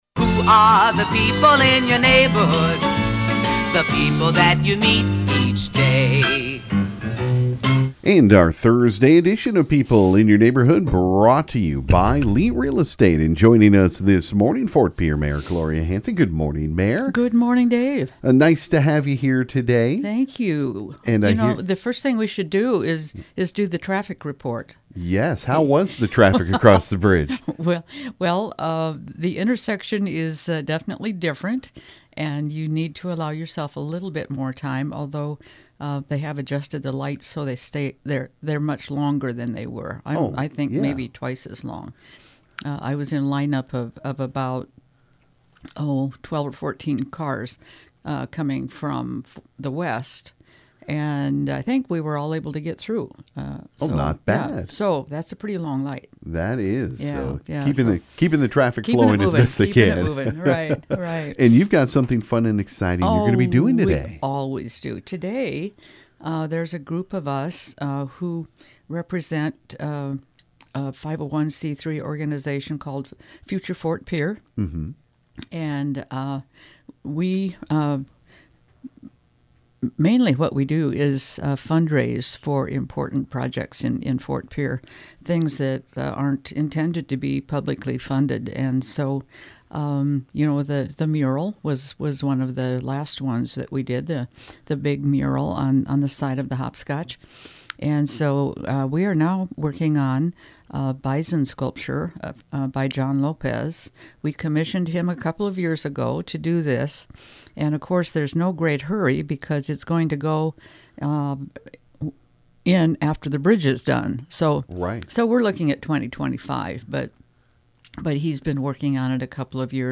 Ft. Pierre Mayor Gloria Hanson was the guest on today’s People In Your Neighborhood on KGFX.